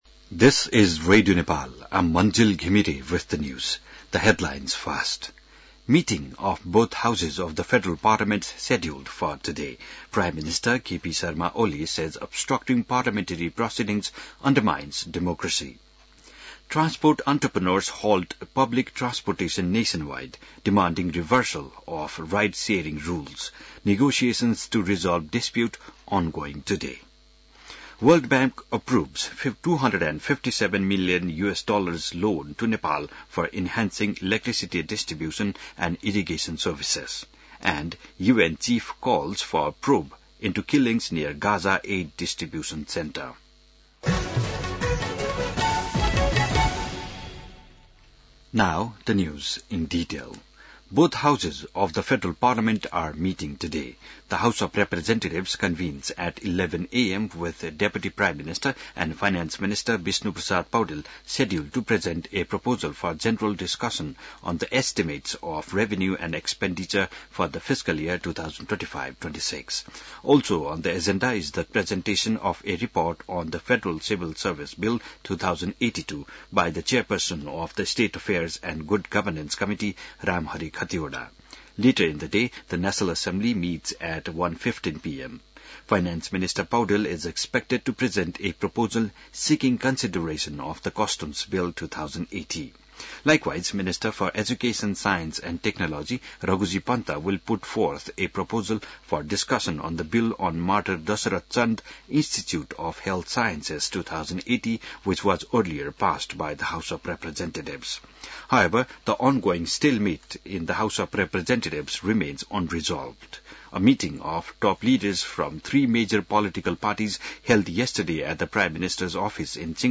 बिहान ८ बजेको अङ्ग्रेजी समाचार : २० जेठ , २०८२